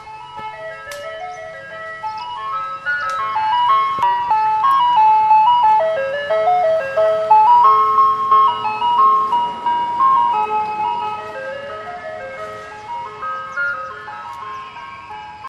Category 🎵 Music
cream Ice truck sound effect free sound royalty free Music